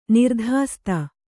♪ nirdhāsta